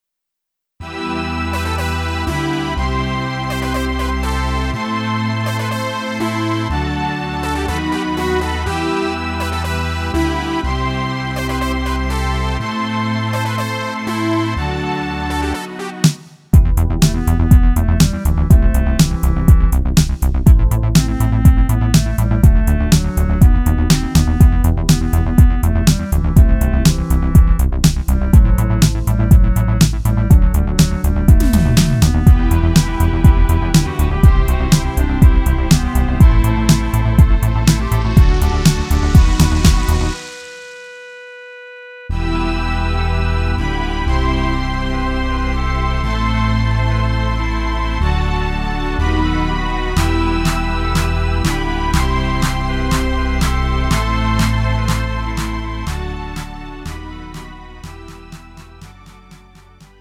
-멜로디MR MR 고음질 반주 다운로드.
음정 -1키
장르 가요